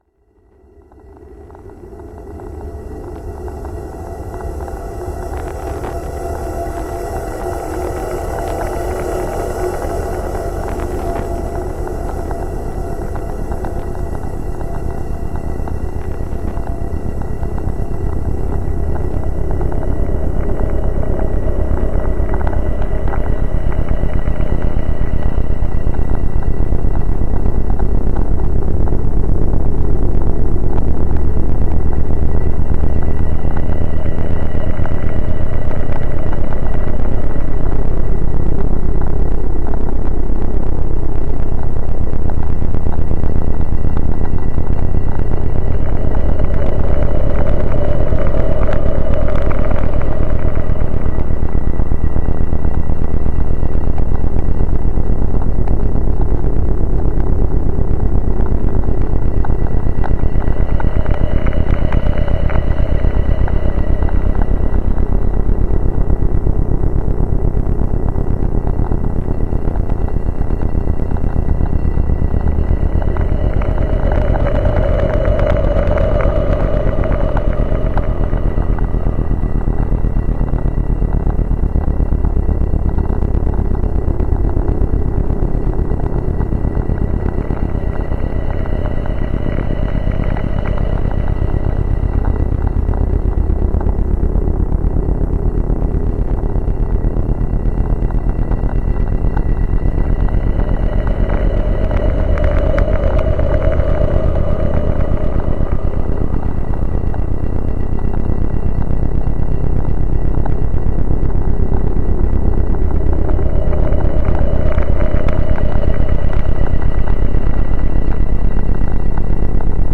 בקשת מידע | סאונד לניקוי אוזניות להורדה
Sound to clean AirPods ｜ Earbuds ｜ Headphones ｜ Ear speakers (DEEP CLEANED).mp3